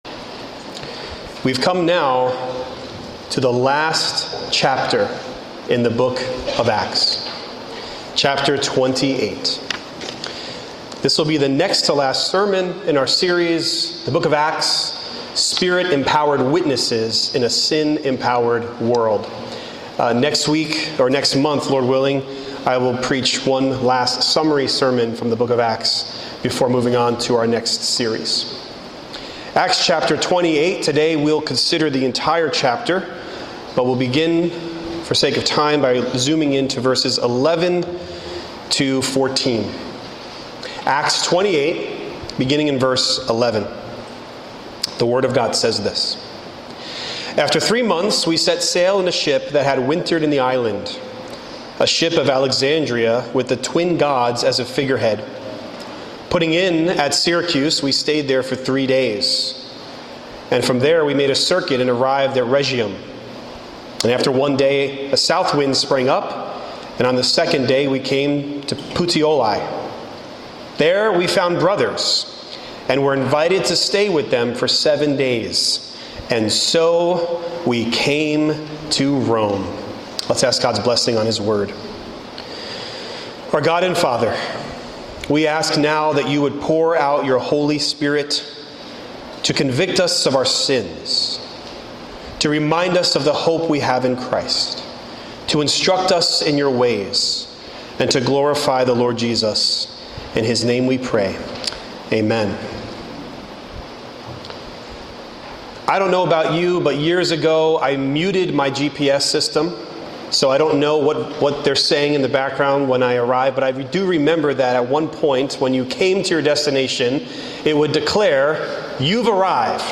And So We Came to Rome | SermonAudio Broadcaster is Live View the Live Stream Share this sermon Disabled by adblocker Copy URL Copied!